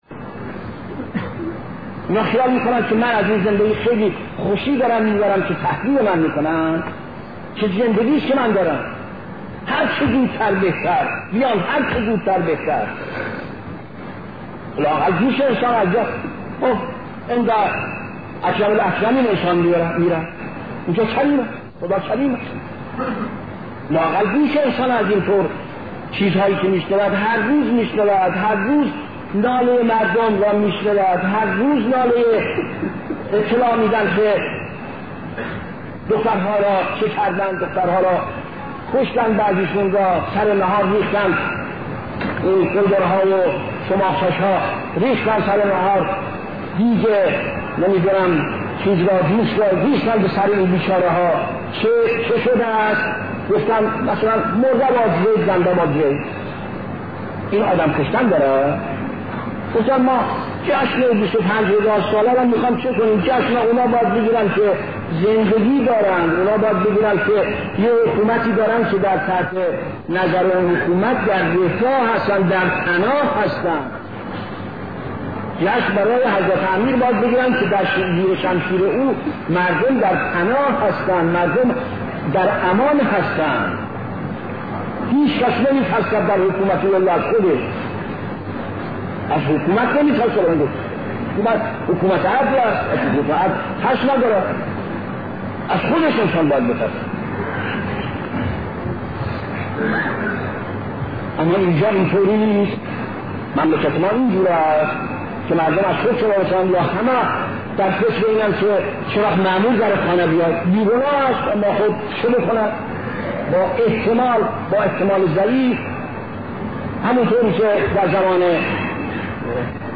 بخش سوم سخنرانی امام خمینی رحمه الله با موضوع حکومت امام علی علیه السلام